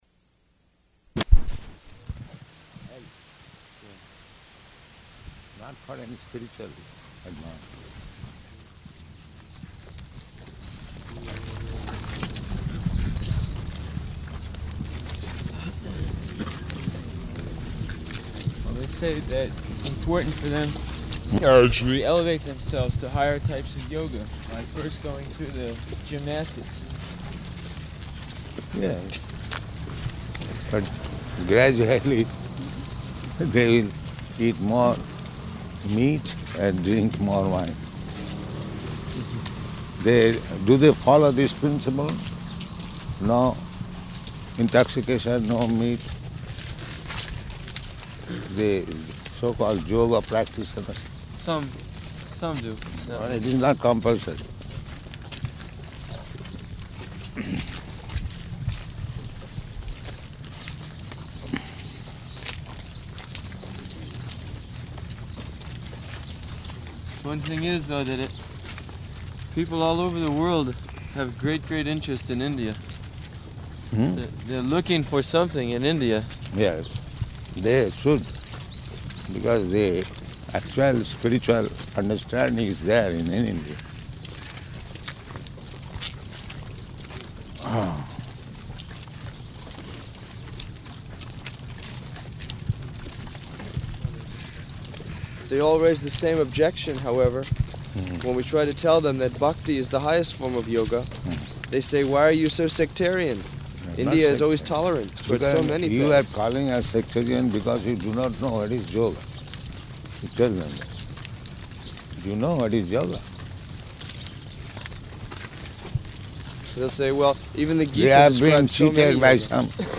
Morning Walk --:-- --:-- Type: Walk Dated: June 14th 1974 Location: Paris Audio file: 740614MW.PAR.mp3 Prabhupāda: ...not for any spiritual advancement.